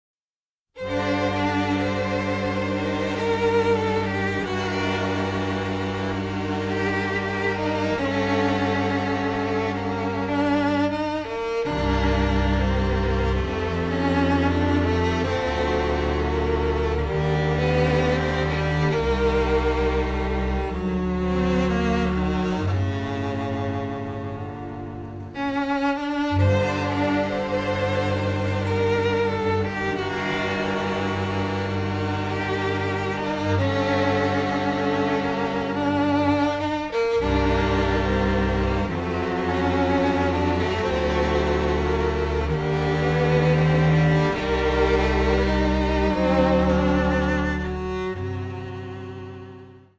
radiant melodramatic score